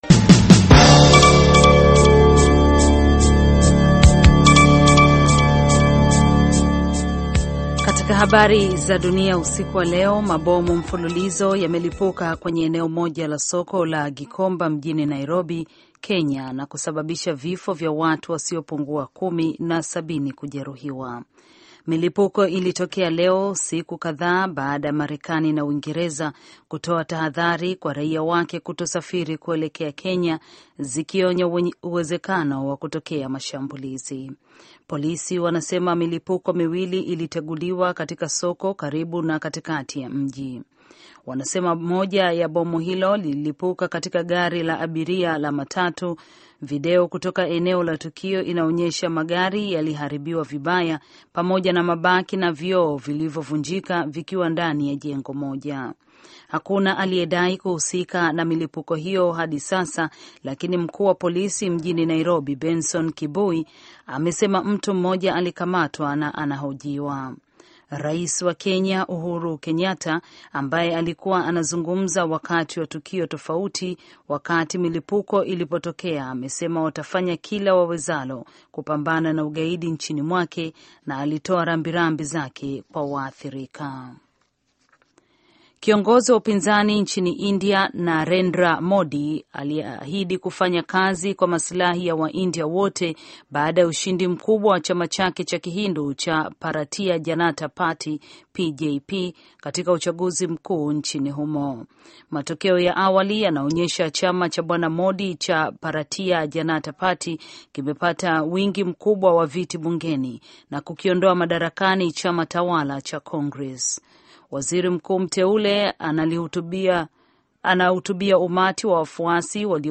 Taarifa ya Habari VOA Swahili - 6:16